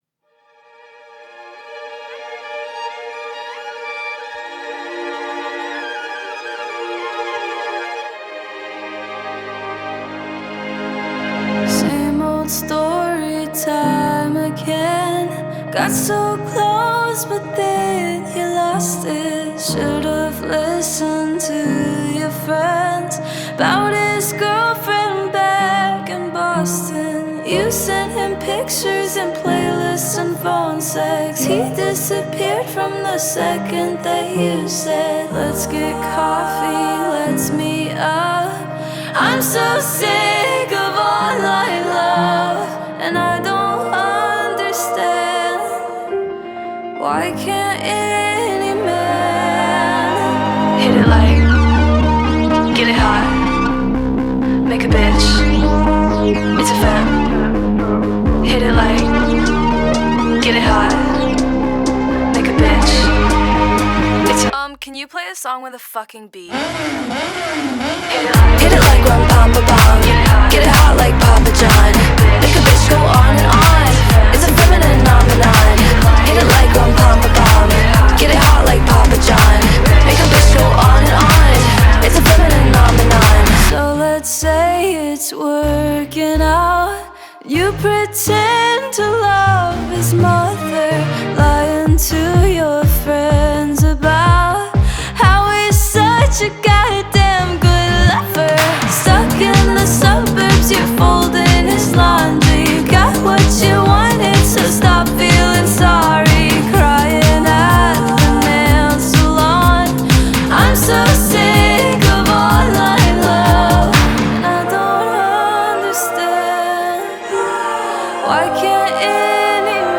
Pop / Synthpop / Queer Pop